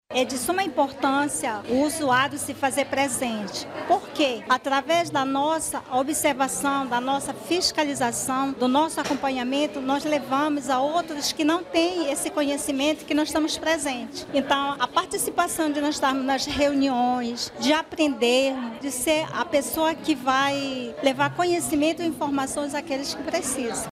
A cerimônia de posse ocorreu no auditório do Centro de Educação Tecnológica do Amazonas – Cetam, localizado no bairro Dom Pedro, zona Oeste da cidade.
SONORA-2-POSSE-CONSELHEIROS-SAUDE-.mp3